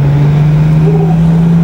the bosh saab bov sounds like an owl.
Compressor surge through a BOV with lips??
9653hoot.wav